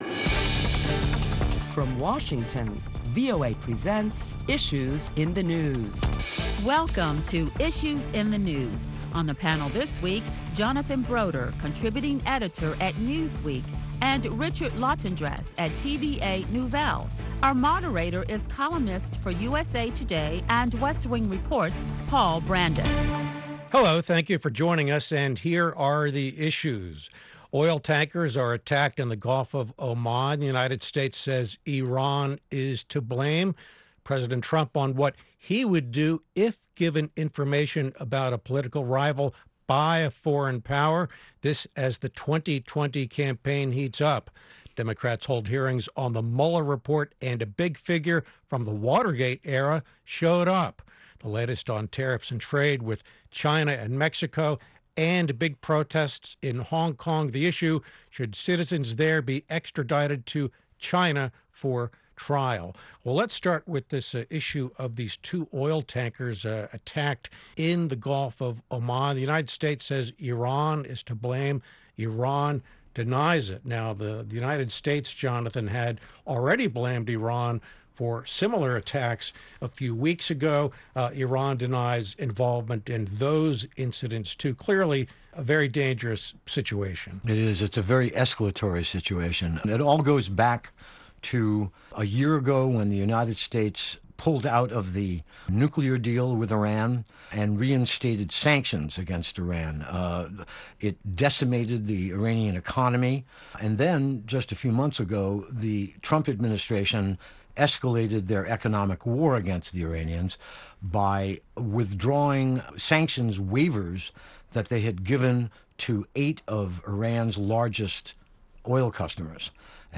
Listen to a panel of leading Washington journalists as they discuss the week's headlines beginning with the recent oil tanker attack in the Gulf of Oman, and why the U.S. says Iran is to blame.